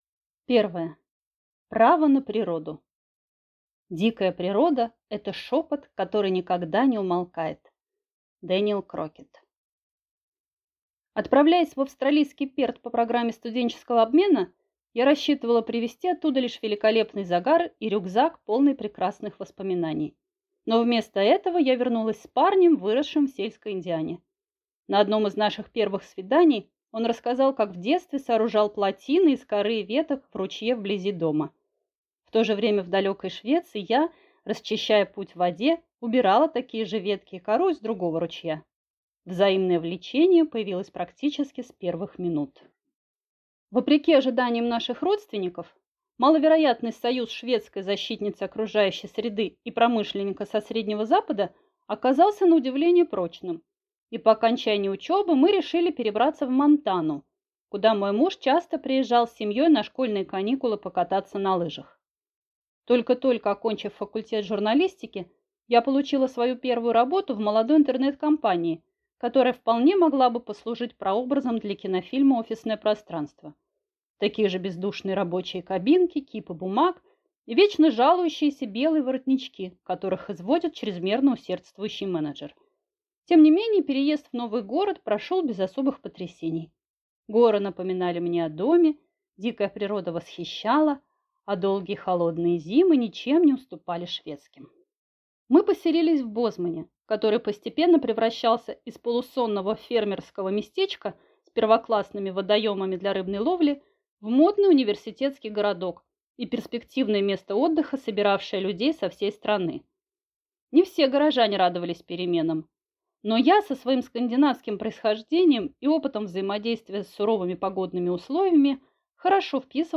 Аудиокнига Не бывает плохой погоды. Как вырастить здоровых, выносливых и уверенных в себе детей: секреты скандинавской мамы (от фрилюфтслив до хюгге) | Библиотека аудиокниг